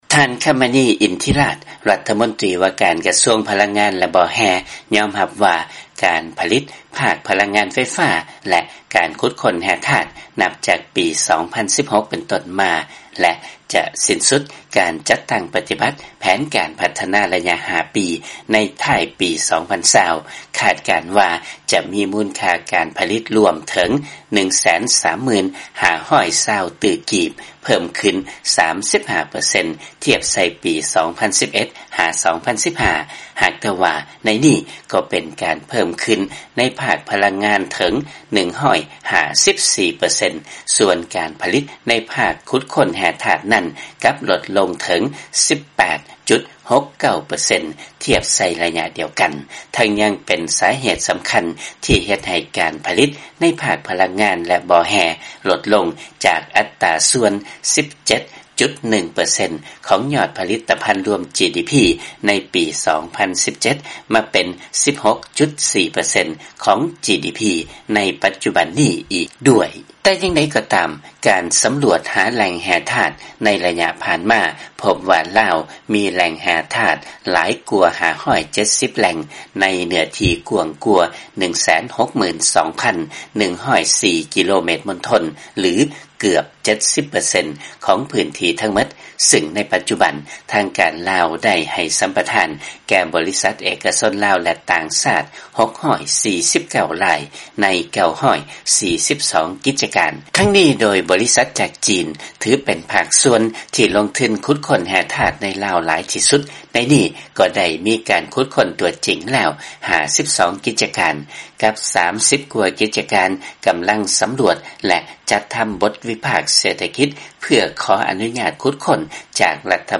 ຟັງລາຍງານ ການຜະລິດ ໃນດ້ານແຮ່ທາດຂອງ ລາວ ຫຼຸດລົງເກີນກວ່າ 18 ເປີເຊັນ ແຕ່ການຜະລິດດ້ານພະລັງງານ ເພີ່ມຂຶ້ນເຖິງ 154 ເປີເຊັນ